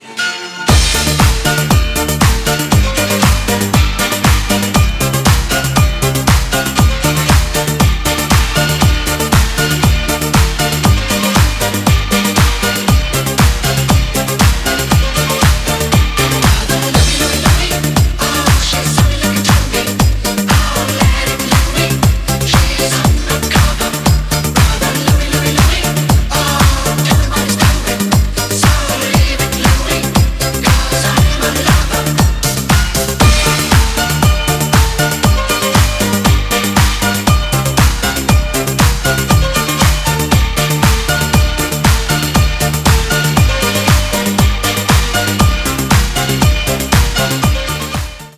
• Качество: 320, Stereo
поп
диско
disco
80-е